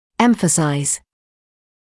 [’emfəsaɪz][‘эмфэсайз]придавать особое значение; подчёркивать, акцентировать